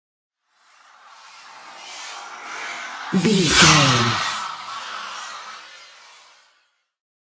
Airy pass by horror squeal
Sound Effects
Atonal
scary
ominous
haunting
eerie
pass by